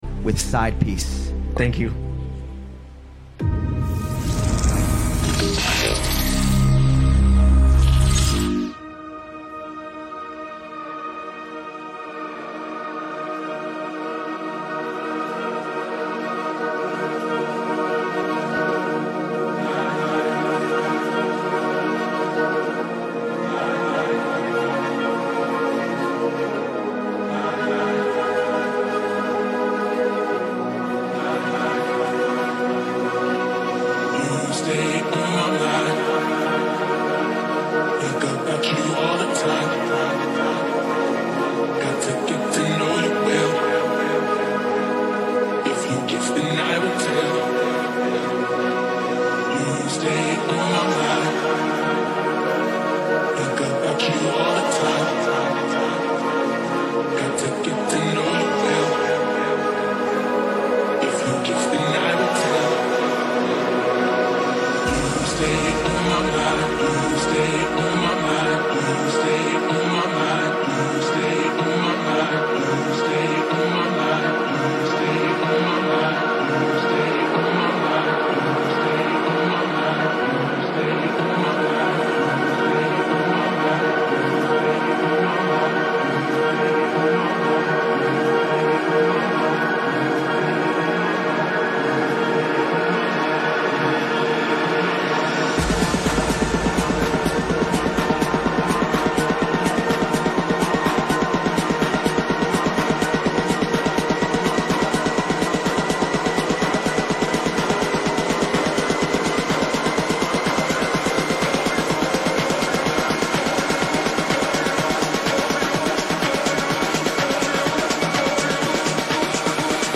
Quarantine Livestreams Genre: House